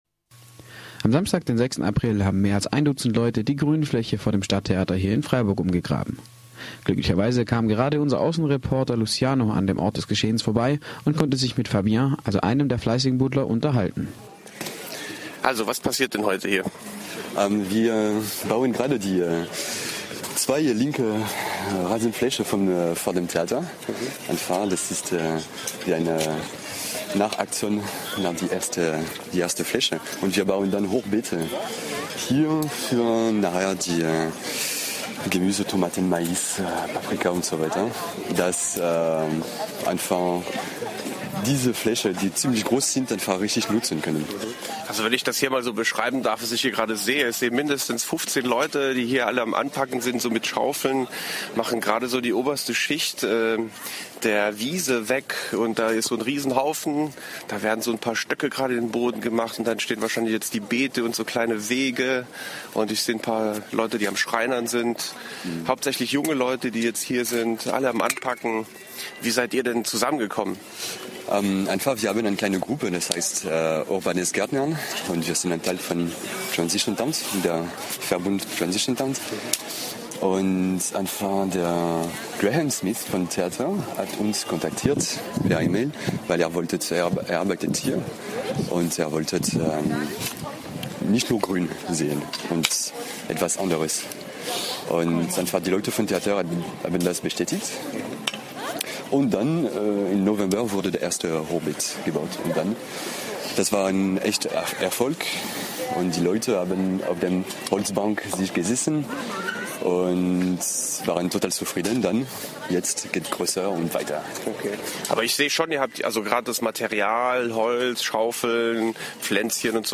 ein kurzes Interview
Urbanes_Gärtnern_Stadttheater_im_RDL_April_2013.mp3